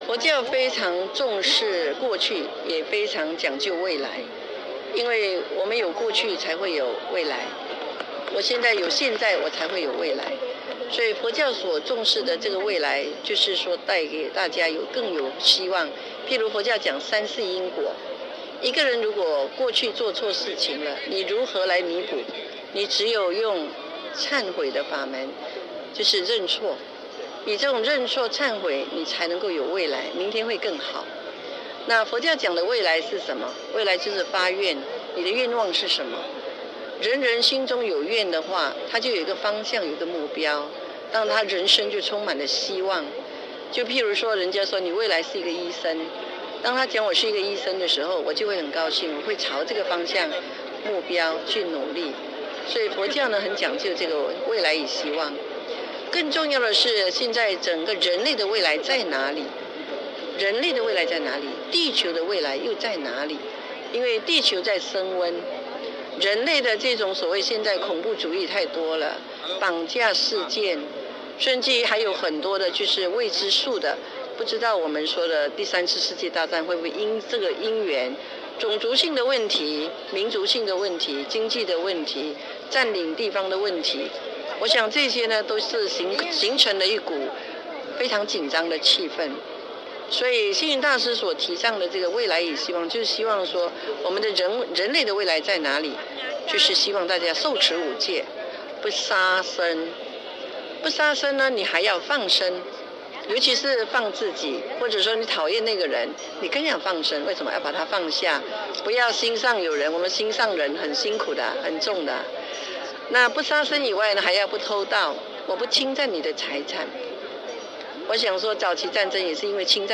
请听来自现场的详细报道。